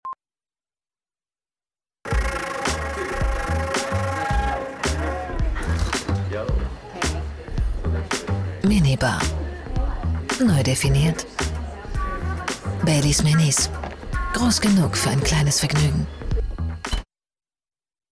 Mittel plus (35-65)
Commercial (Werbung)